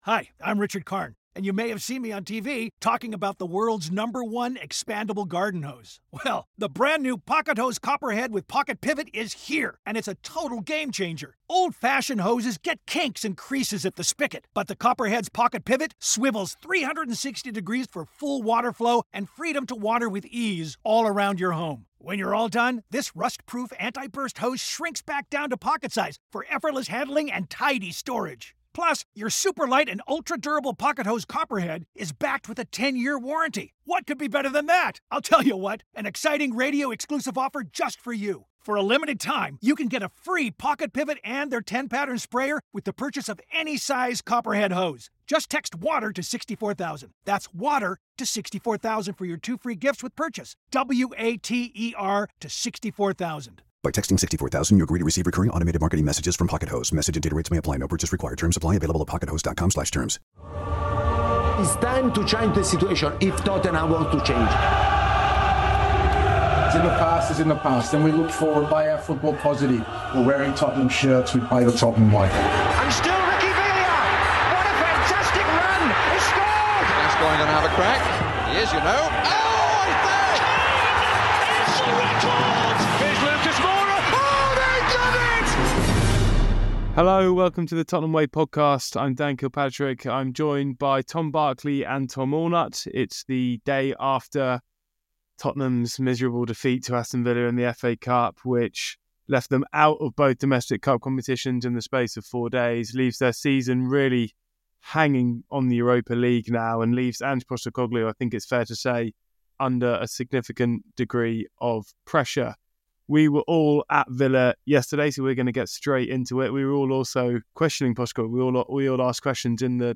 Is the manager right to accuse his critics of "skewed", "agenda-driven" analysis and suggest that injuries are the sole cause of his side's woeful form? Or is there more he could have done in the circumstances, dire as they are? The panel get stuck in and don't always agree.